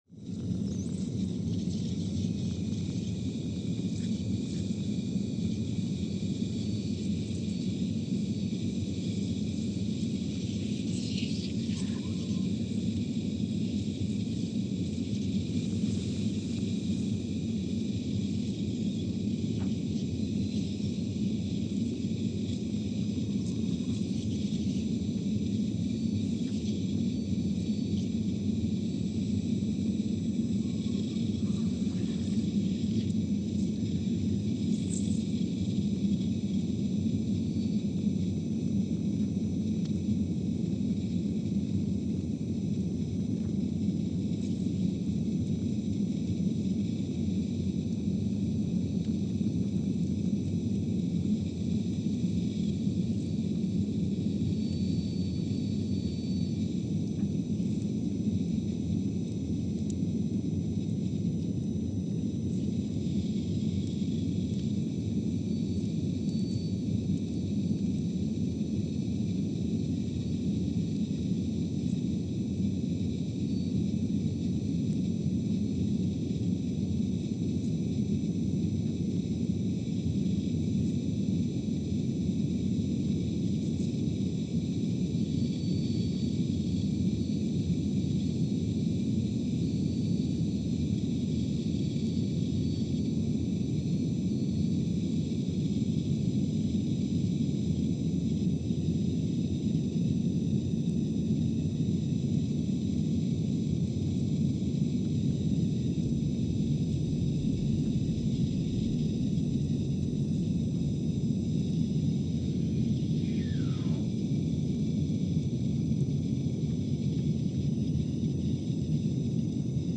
Scott Base, Antarctica (seismic) archived on February 9, 2020
Sensor : CMG3-T
Speedup : ×500 (transposed up about 9 octaves)
Loop duration (audio) : 05:45 (stereo)
SoX post-processing : highpass -2 90 highpass -2 90